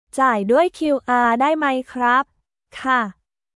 ジャーイ ドゥアイ キューアー ダイ マイ クラップ/カー